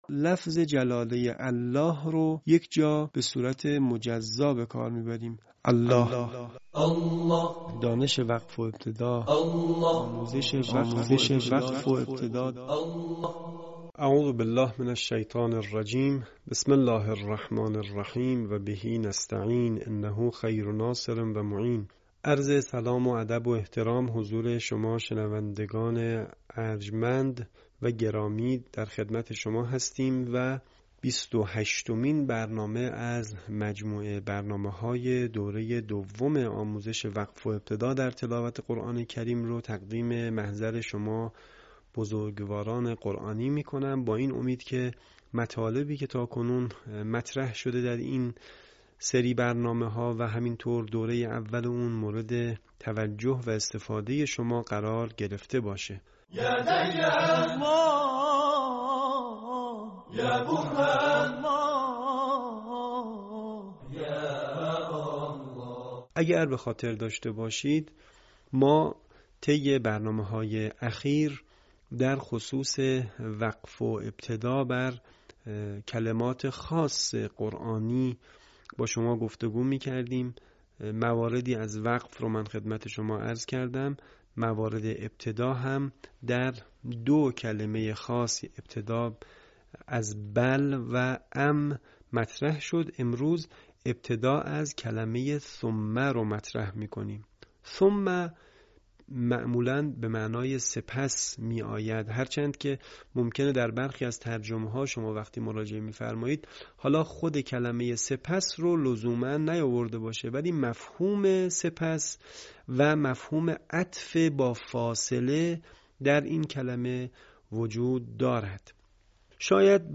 یکی از مهم‌ترین سیاست‌های رسانه ایکنا نشر مبانی آموزشی و ارتقای سطح دانش قرائت قرآن مخاطبان گرامی است.